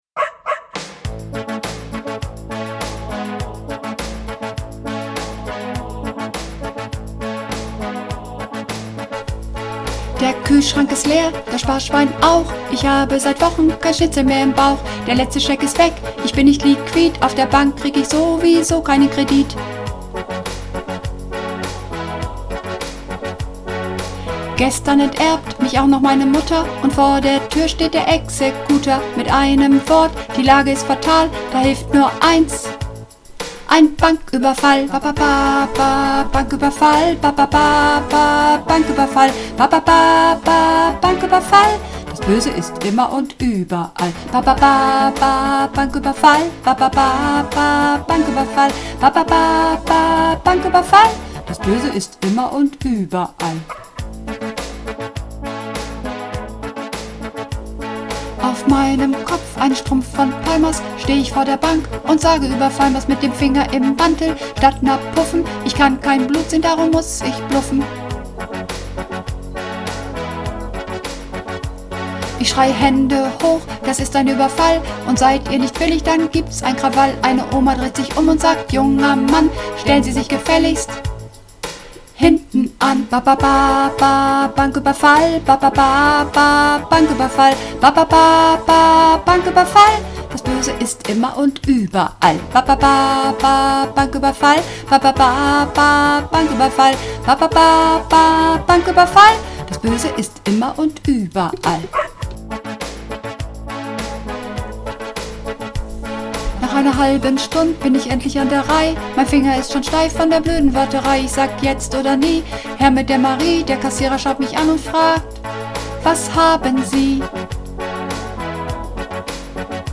Übungsaufnahmen - Ba-Ba-Banküberfall
Ba-Ba-Banküberfall (Sopran)